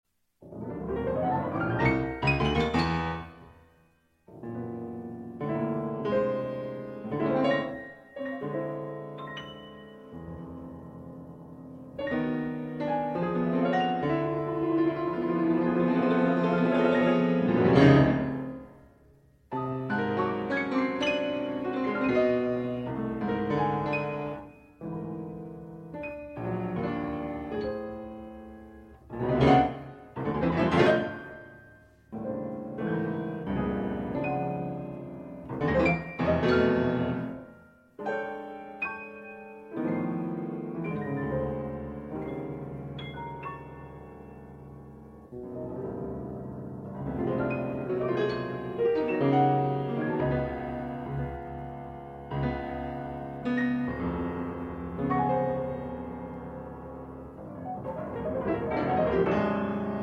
Disklavier